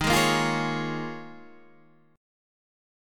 D#9sus4 chord